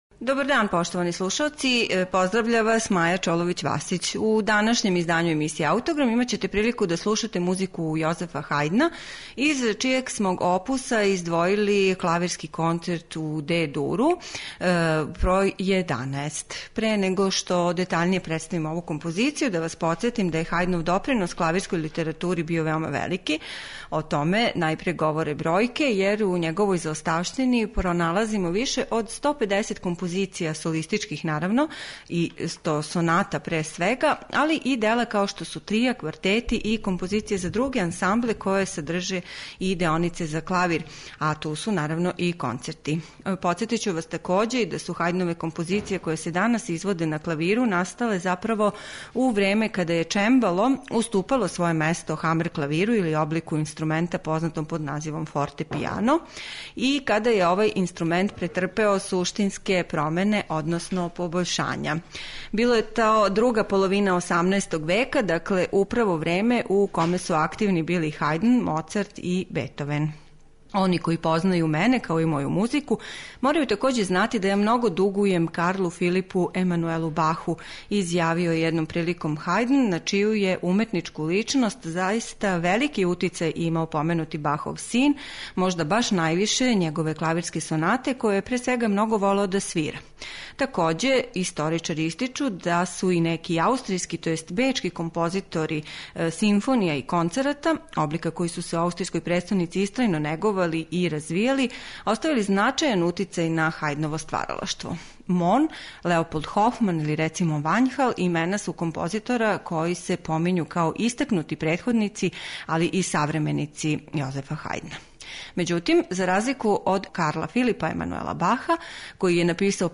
Концерт за клавир и оркестар бр. 11 у Де-дуру, Јозефа Хајдна, компонован је између 1780. и 1783. Дело оригинално писано за чембало или фортепиано и познато по живахном финалном ставу - Ронду у мађарском стилу